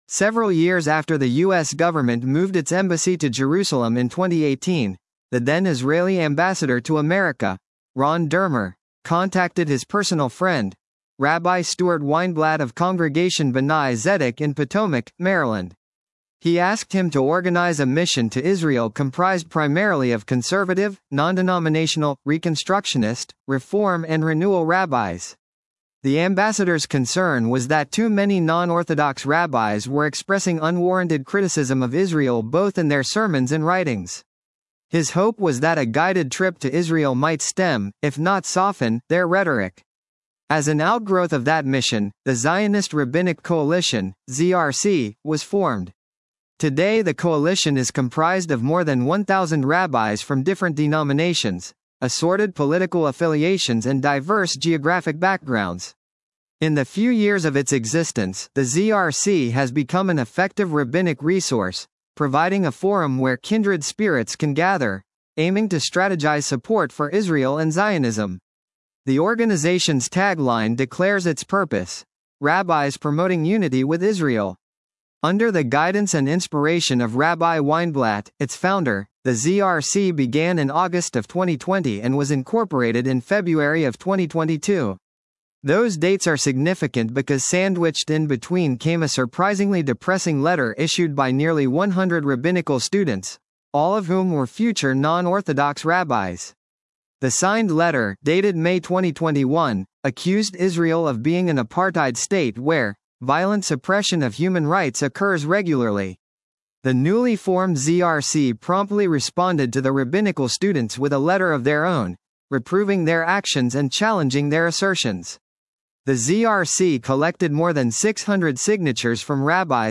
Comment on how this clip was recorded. A session from the Zionist Rabbinic Coalition conference